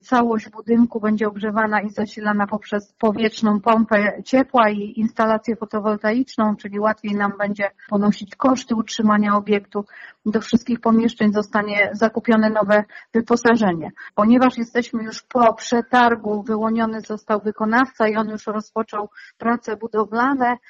Mówi wójt gminy Kłodawa, Anna Mołodciak: